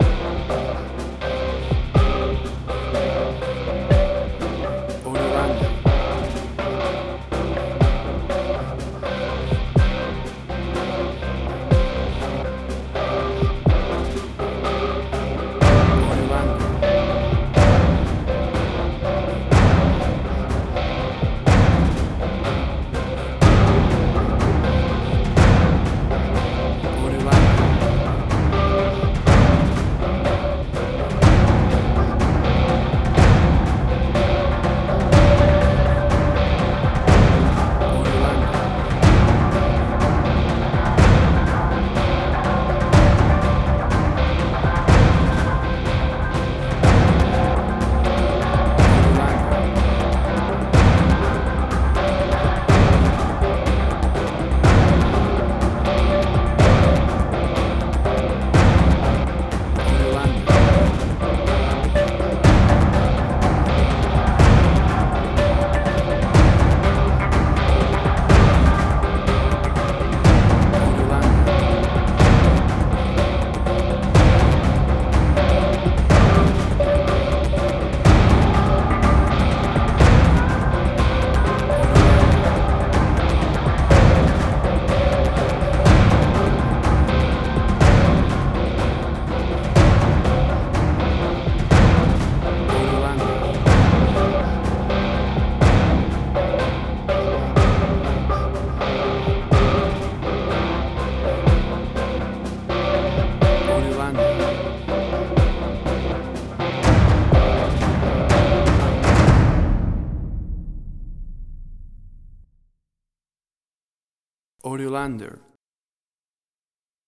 Suspense, Drama, Quirky, Emotional.
Tempo (BPM): 123